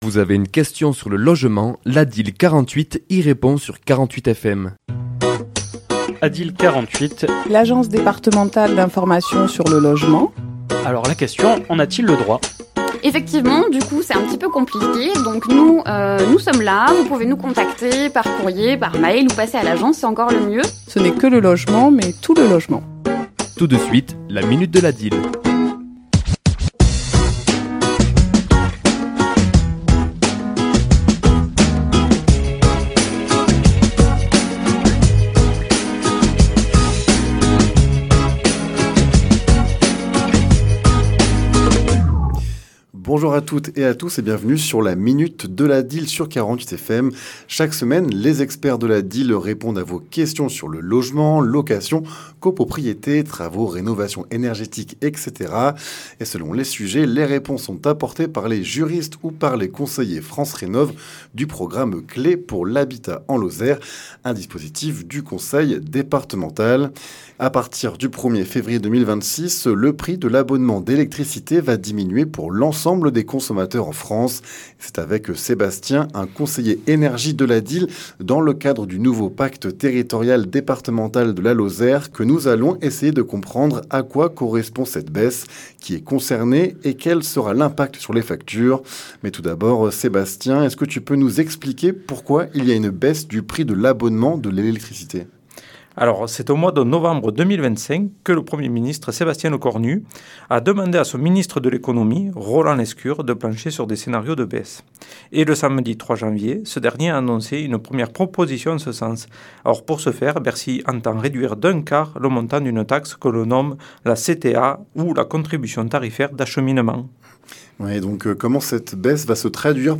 Chronique diffusée le mardi 24 février à 11h et 17h10